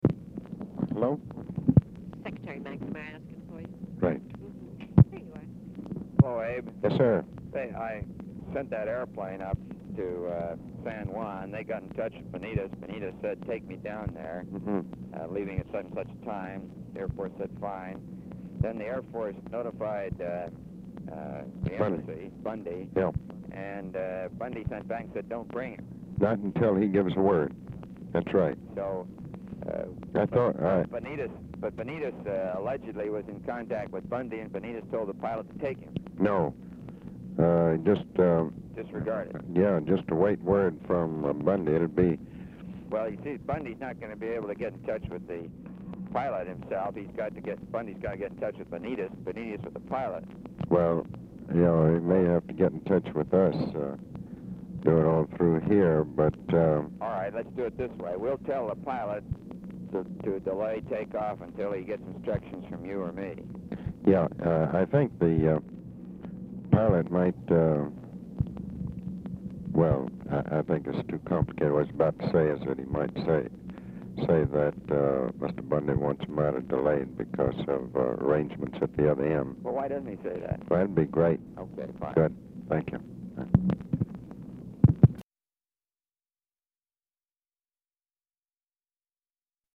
Telephone conversation # 7923, sound recording, ABE FORTAS and ROBERT MCNAMARA, 5/19/1965
Format Dictation belt
Location Of Speaker 1 White House Situation Room, Washington, DC